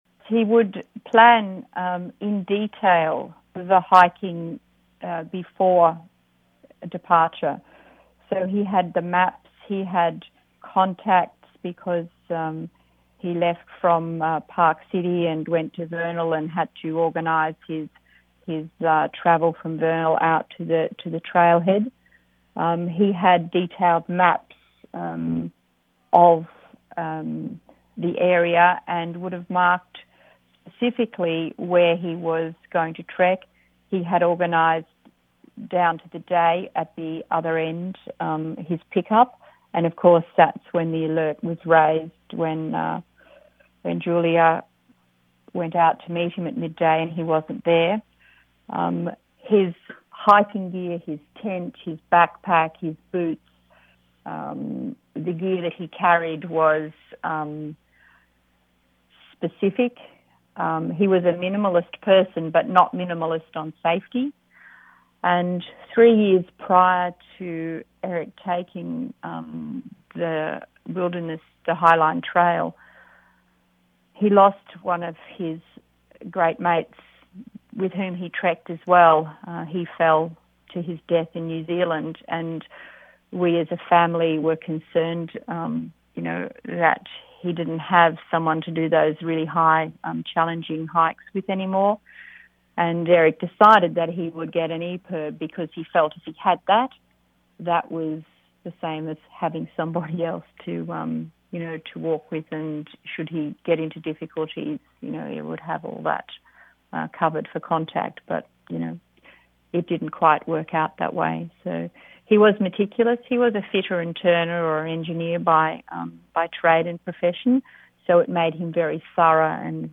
interview clip